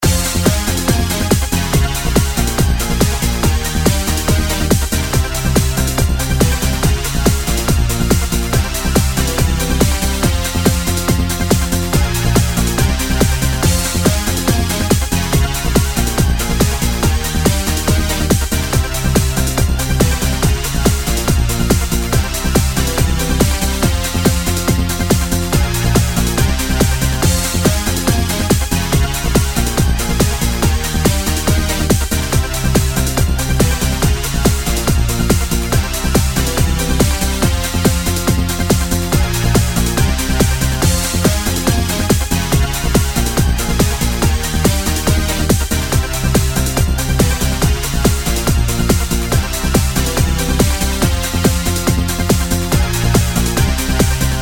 18.纯音乐 - 出场 欢快.mp3